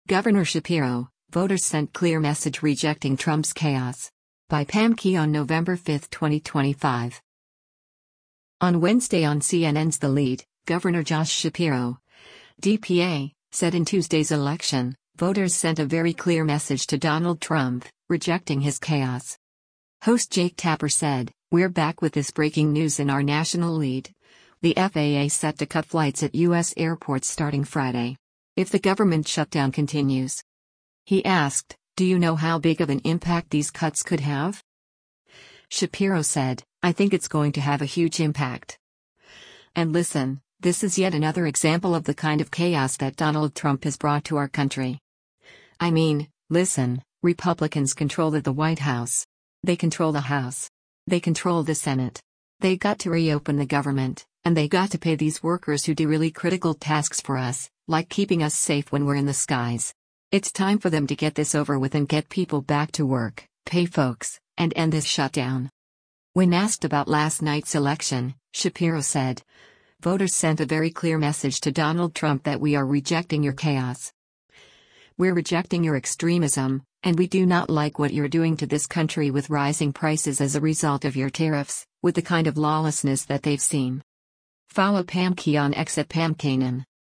On Wednesday on CNN’s “The Lead,” Gov. Josh Shapiro (D-PA) said in Tuesday’s election, voters sent a “very clear message” to Donald Trump, rejecting his “chaos.”